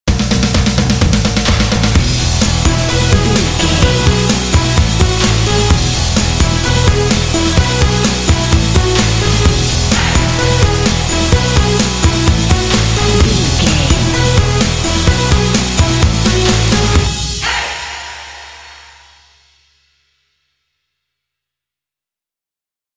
This indie track contains vocal “hey” shots.
Ionian/Major
lively
cheerful/happy
drums
bass guitar
electric guitar
percussion
synthesiser
synth-pop
alternative rock